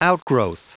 Prononciation audio / Fichier audio de OUTGROWTH en anglais
Prononciation du mot : outgrowth
outgrowth.wav